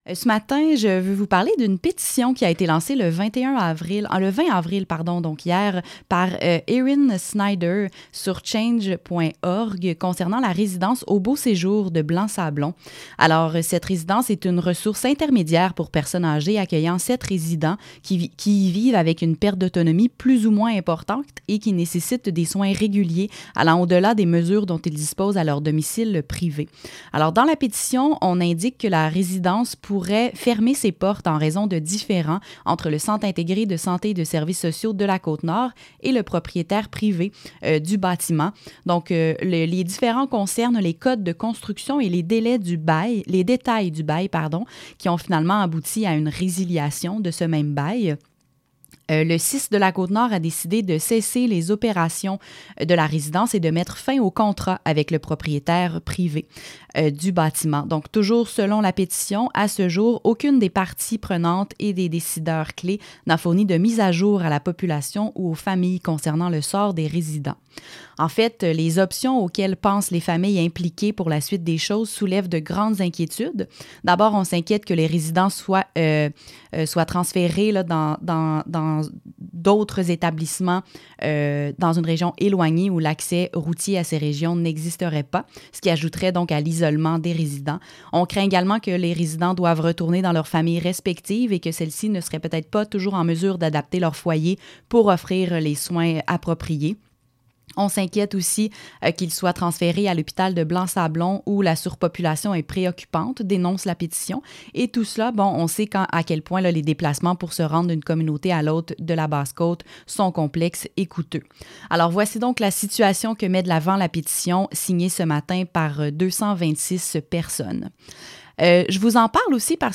Aux-beaux-séjours-segment-radio.mp3